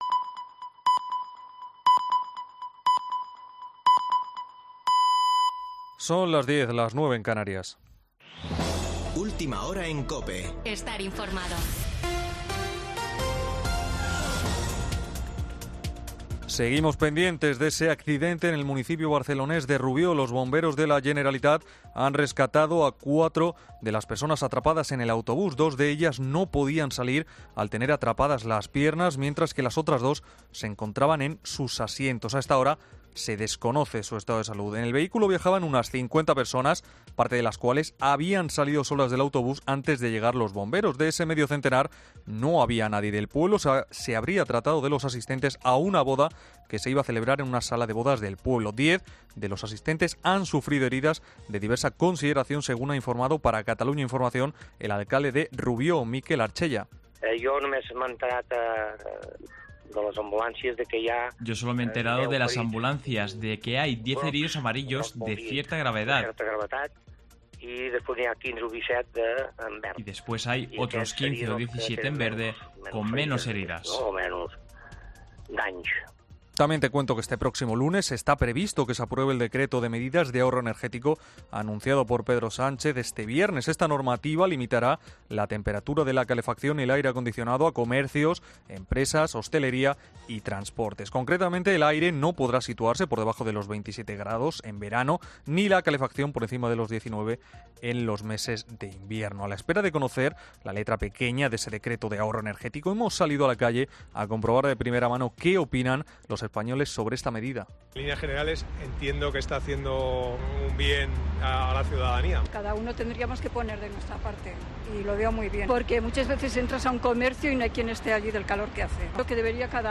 Boletín de noticias de COPE del 30 de julio de 2022 a las 22.00 horas